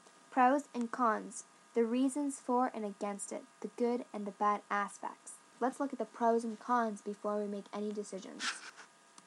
英語ネイティブによる発音は以下のリンクをクリックしてください。